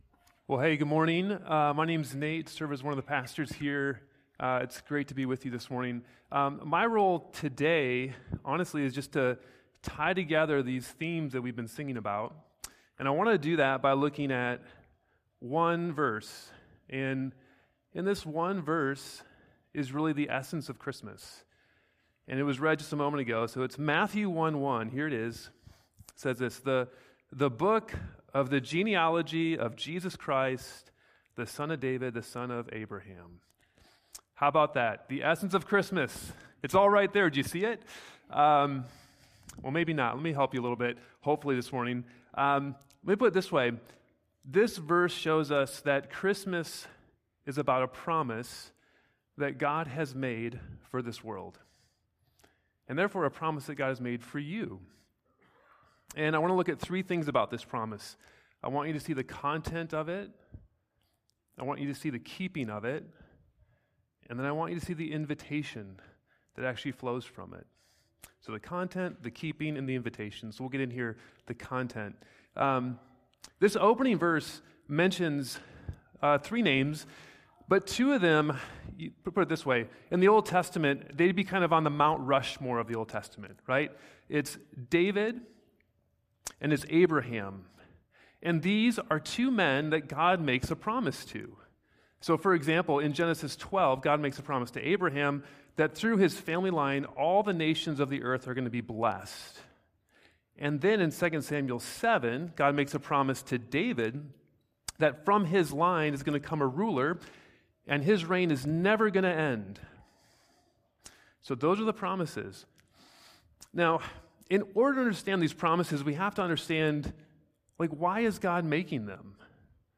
Weekly sermons from Redeemer City Church in Madison, Wisconsin, which seeks to renew our city through the gospel.